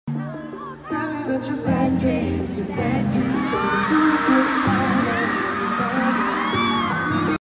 World Tour in Macau